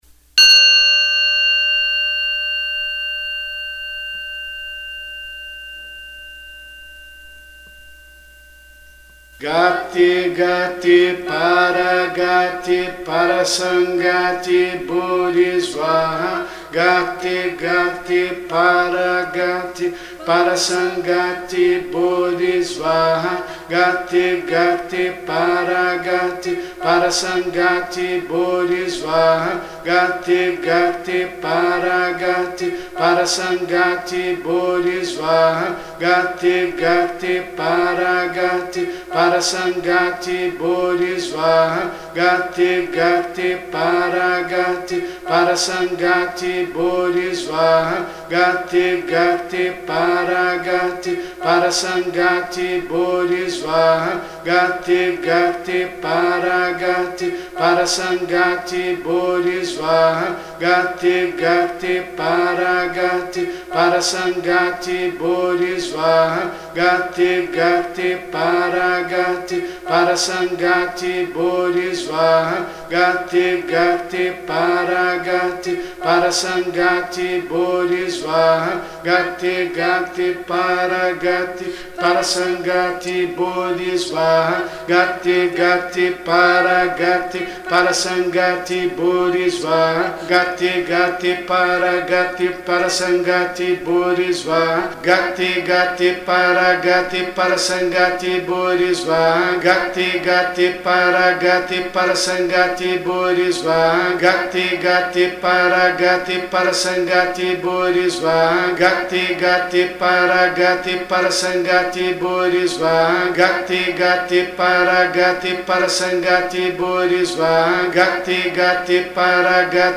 MANTRAS PARA O SENHOR GAUTAMA BUDA
GATE GATE PARAGATE PARASAMGATE BODI SVARRA